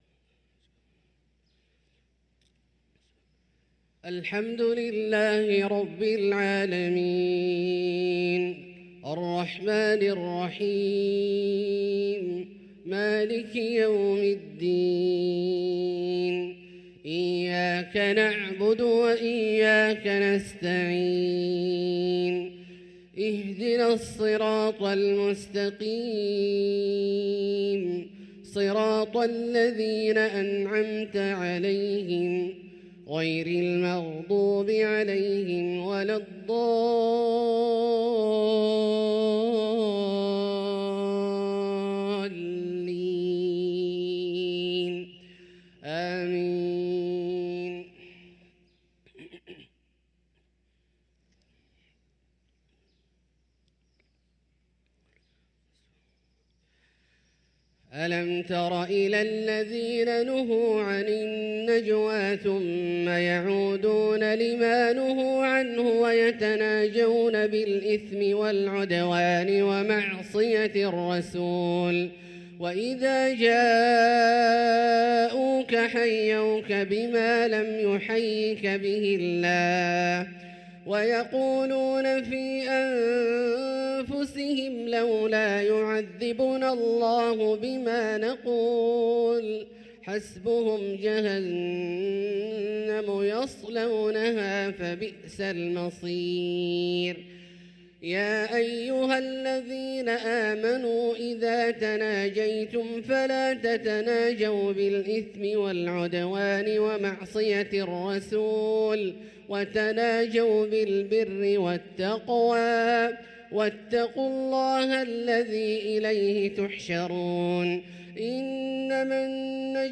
صلاة الفجر للقارئ عبدالله الجهني 7 جمادي الأول 1445 هـ
تِلَاوَات الْحَرَمَيْن .